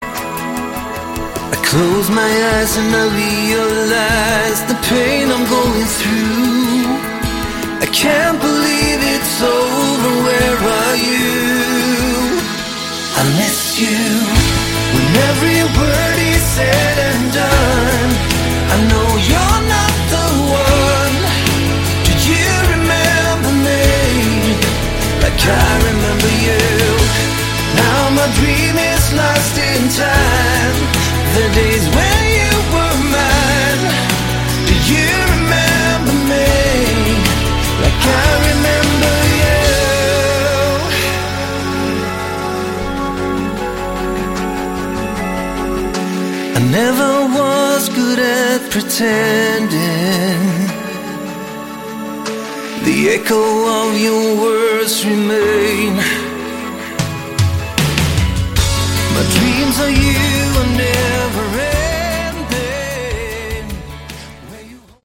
Category: Melodic Rock
lead and backing vocals, acoustic guitars
electric guitars
lead guitars
bass and backing vocals
keyboards
drums